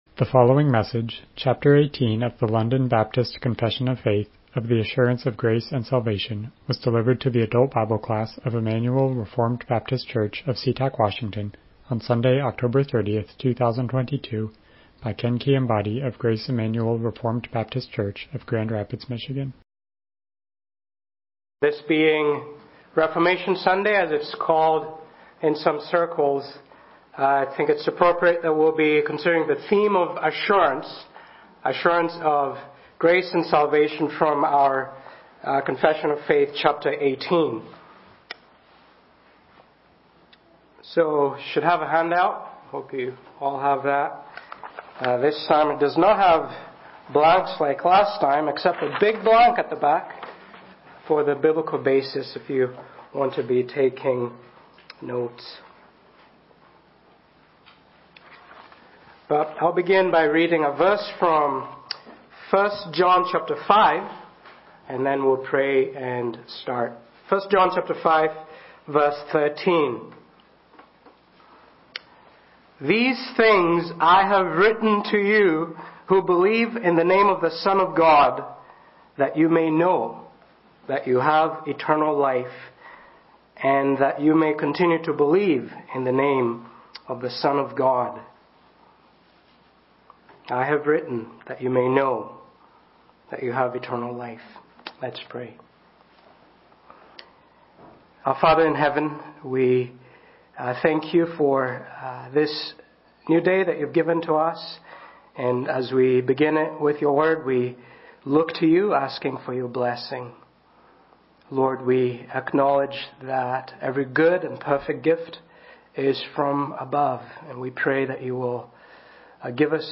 Service Type: Sunday School Topics: Confession of Faith